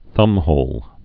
(thŭmhōl)